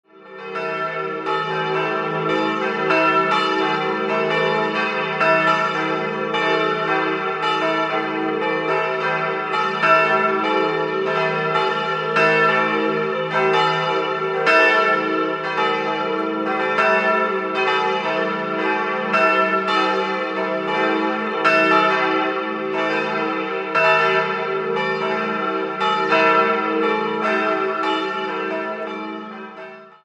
Jh. der Stifterfamilie und der Äbtissinnen. 3-stimmiges TeDeum-Geläute: e'-g'-a' Die mittlere Glocke g' stammt von 1981 aus der Gießerei Heidelberg und wiegt 762 kg. Die anderen zwei wurden 1953 bei Junker in Brilon gegossen.